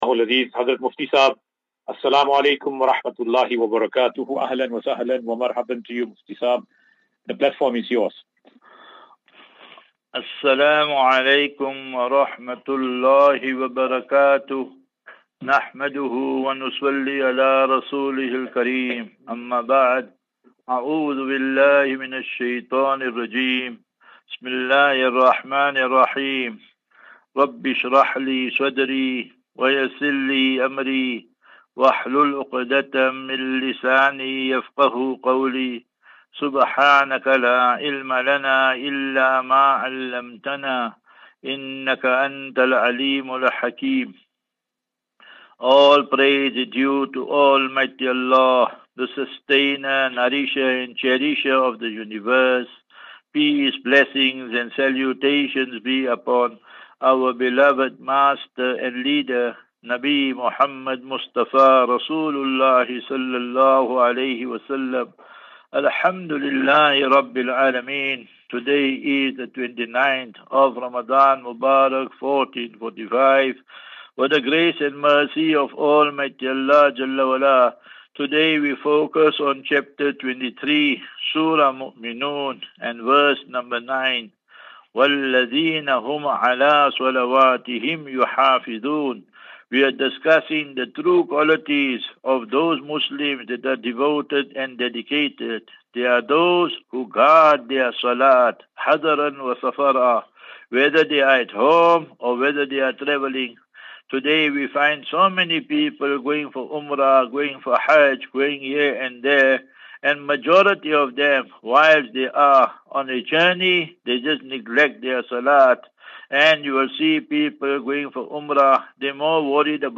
As Safinatu Ilal Jannah Naseeha and Q and A 9 Apr 09 April 2024.